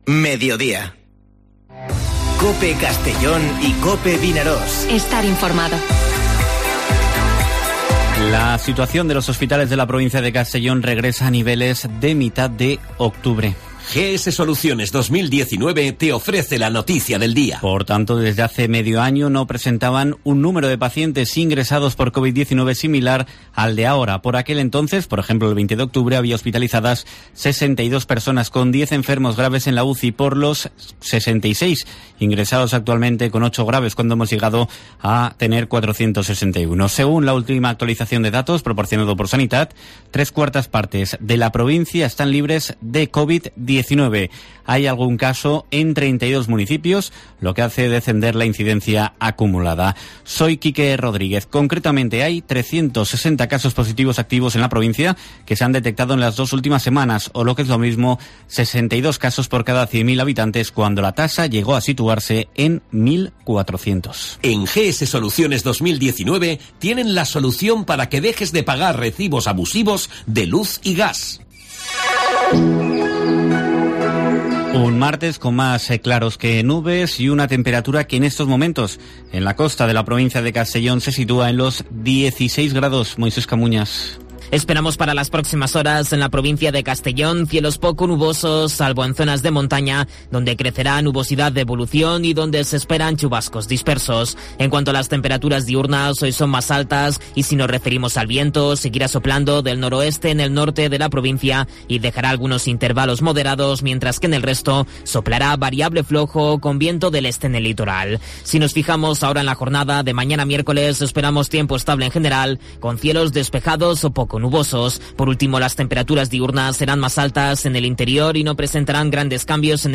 Informativo Mediodía COPE en la provincia de Castellón (09/03/2021)